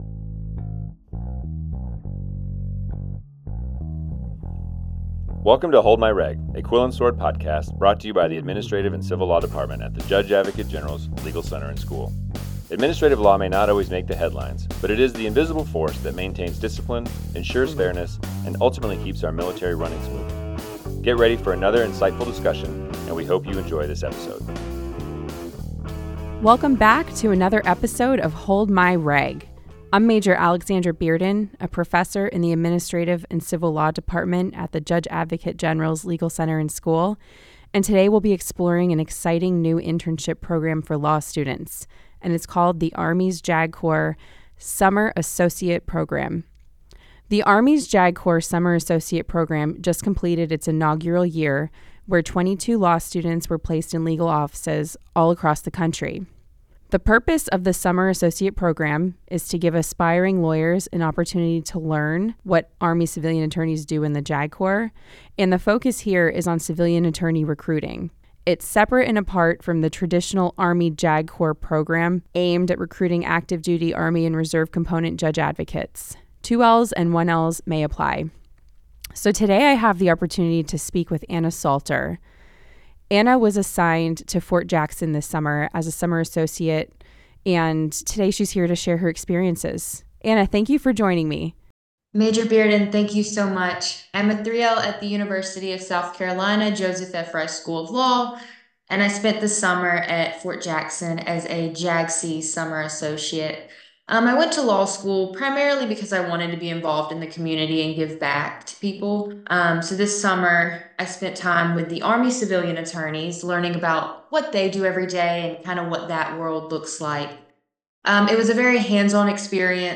Both guests share their unique experi-ences and offer valuable insight to those who may be interested in applying to the program and ultimately joining the JAG Corps as federal civilian employees.